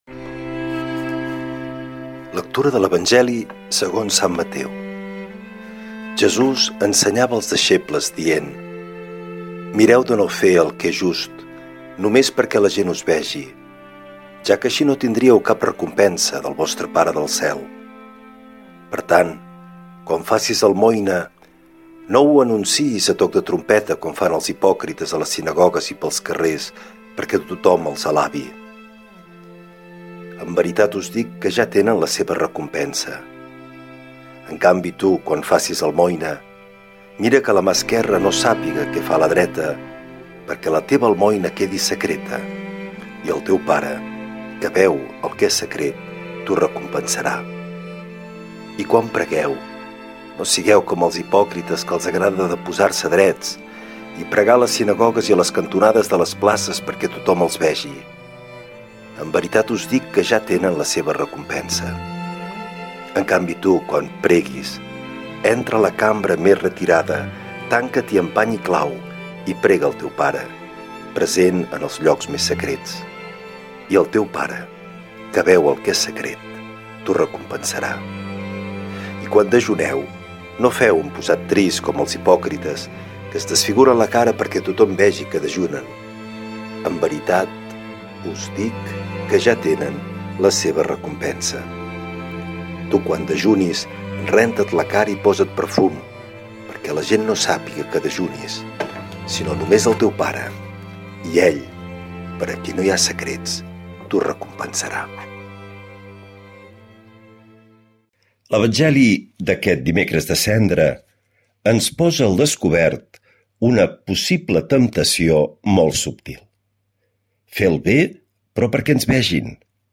L’Evangeli i el comentari de dimecres 18 de febrer del 2026.
Lectura de l’evangeli segons sant Mateu